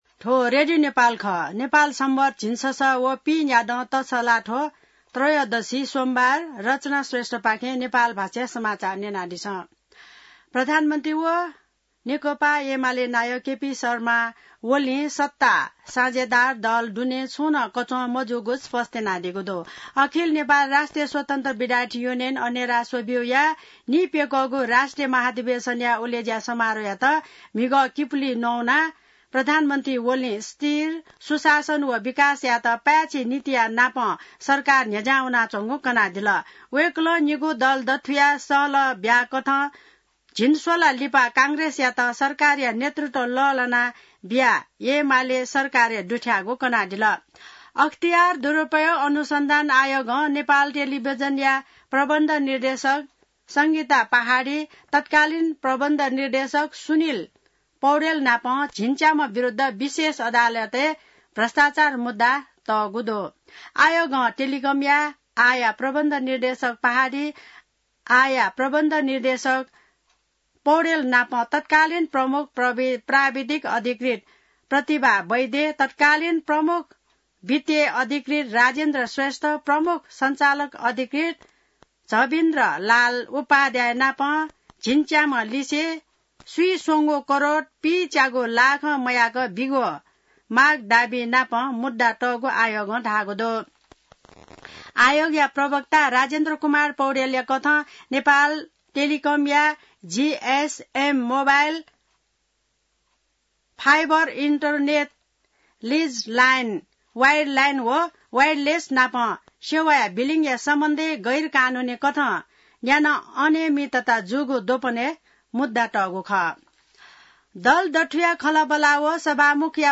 नेपाल भाषामा समाचार : २६ जेठ , २०८२